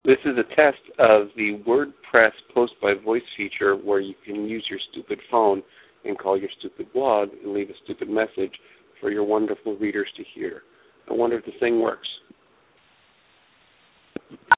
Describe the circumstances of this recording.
They give you a number, you call it, leave a message and it’s posted to your blog.